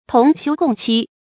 同休共戚 tóng xiū gòng qī
同休共戚发音
成语注音 ㄊㄨㄙˊ ㄒㄧㄨ ㄍㄨㄙˋ ㄑㄧ